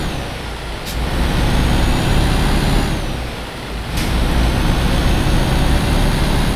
Index of /server/sound/vehicles/lwcars/truck_2014actros